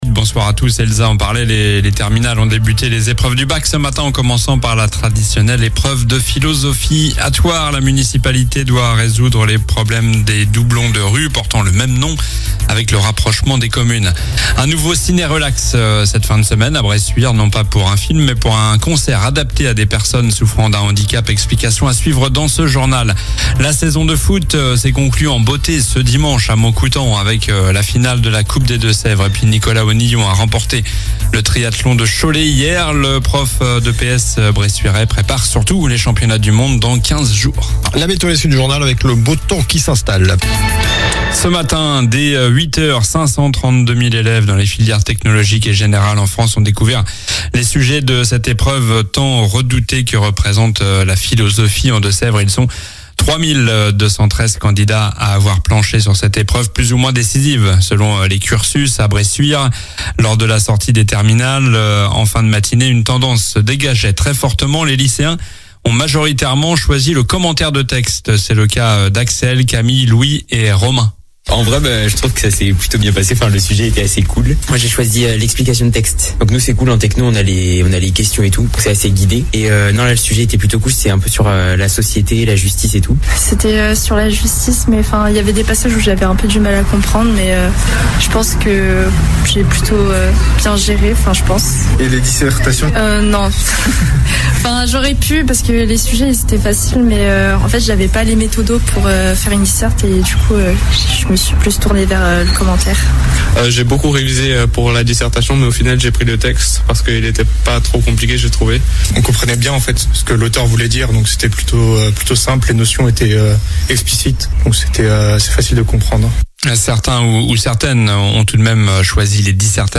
Journal du lundi 16 juin (soir)